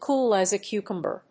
アクセント(as) cóol as a cúcumber